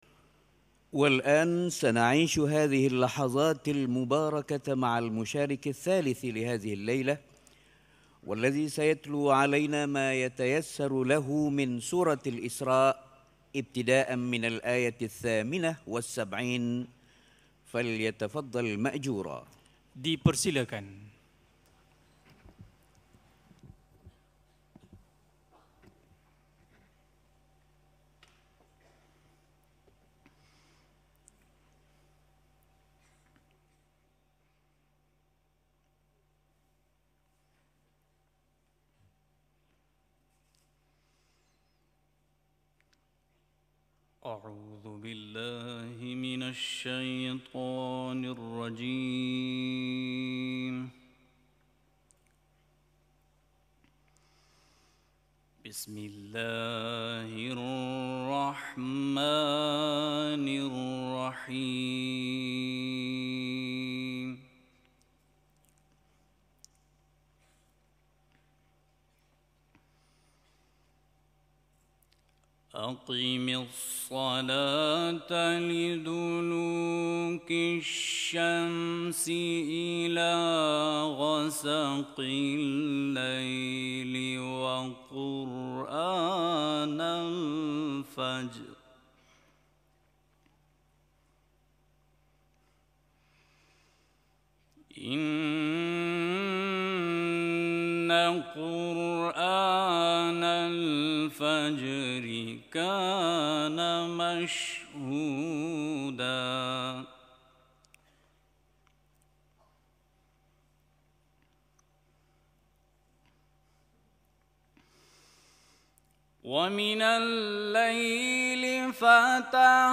گروه فعالیت‌های قرآنی: شب گذشته در مسابقات بین‌المللی مالزی پنج قاری از میان آقایان شرکت‌کننده تلاوت داشتند که تحلیلی از تلاوت آنها خواهیم داشت.